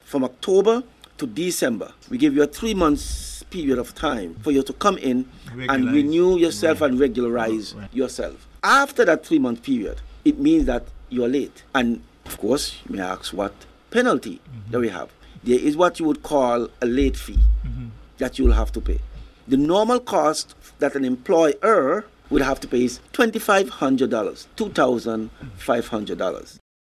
The Nevis Island Administration (NIA) continues to issue Work Permits to foreigners and Permanent Secretary in the Premier’s Ministry, Wakely Daniel, had this to say: